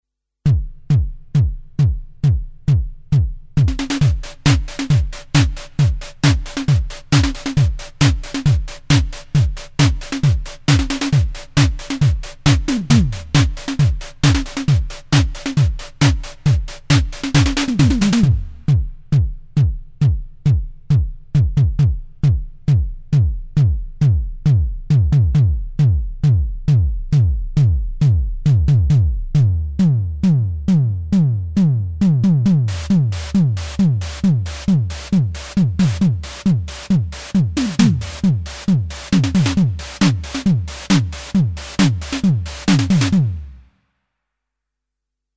1_SDS-8-demo1.mp3